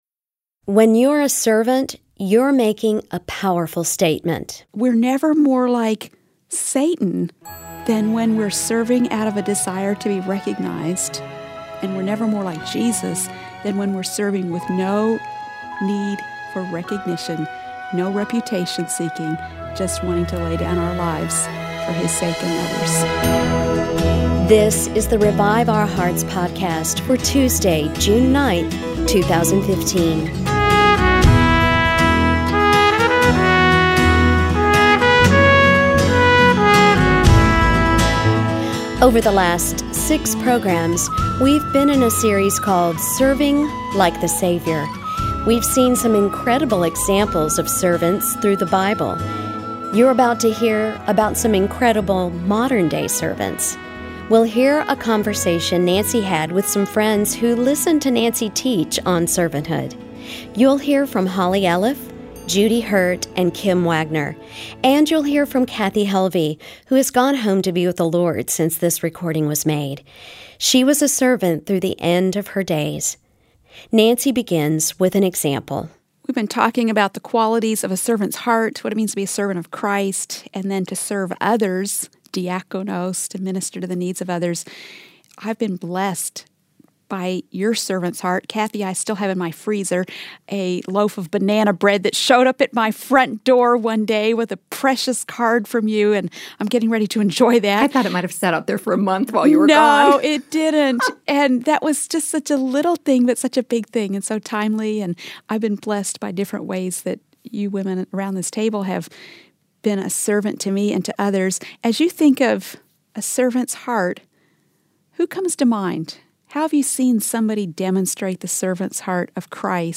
But what does it look like in real life? We’ll hear from several women who flesh it out.